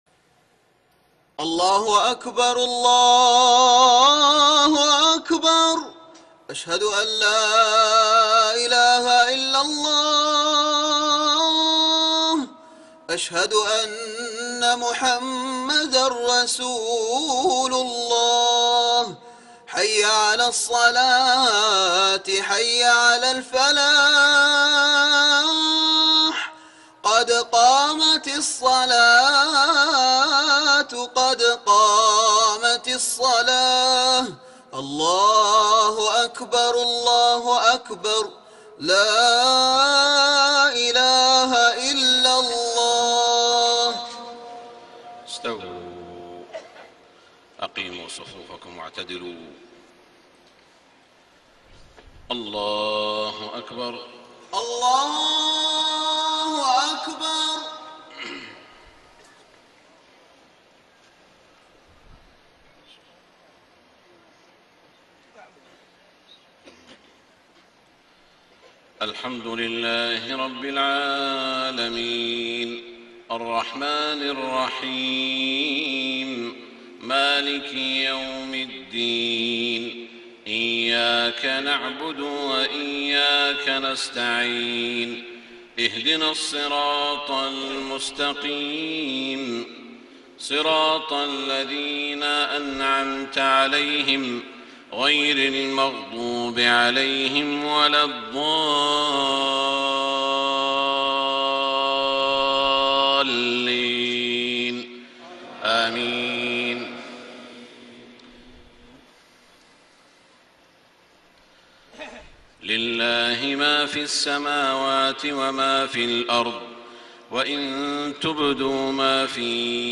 صلاة المغرب 21 رجب 1433هـ خواتيم سورة البقرة > 1433 🕋 > الفروض - تلاوات الحرمين